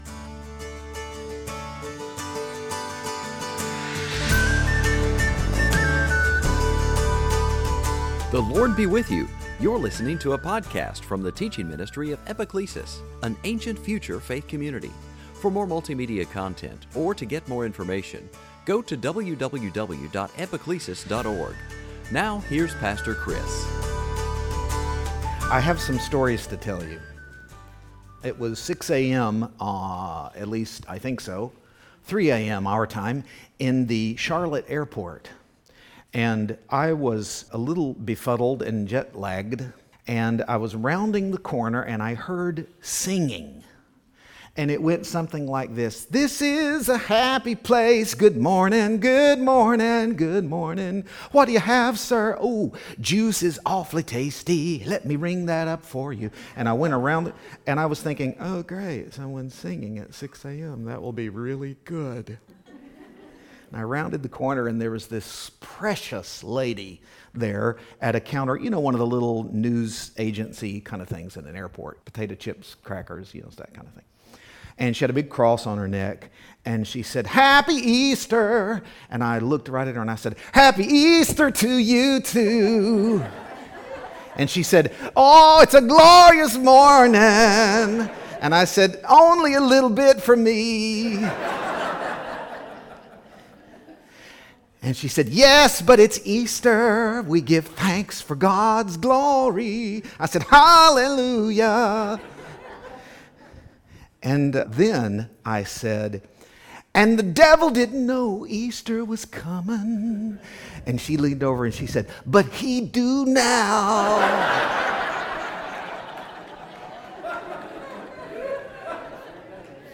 2018 Sunday Teaching Easter resurrection story Easter Sunday